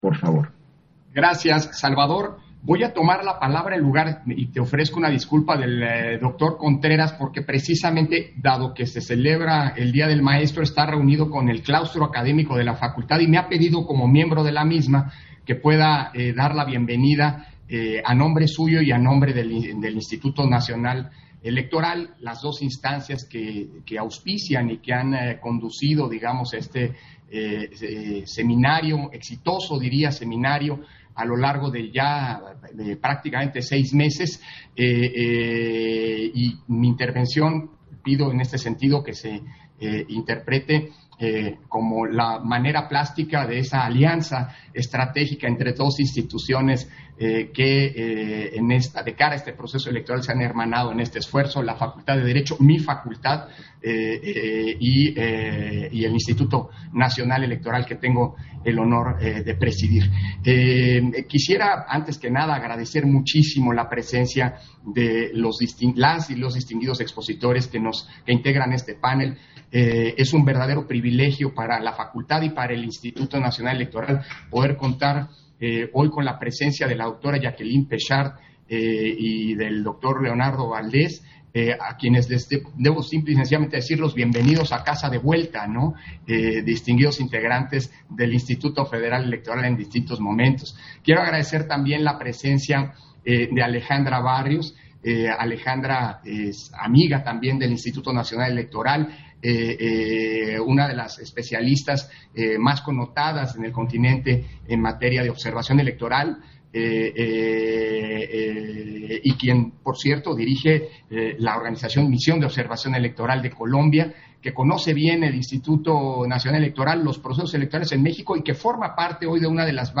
Intervención de Lorenzo Córdova, en la novena sesión del Seminario Permanente, Democracia y Elecciones en el Mundo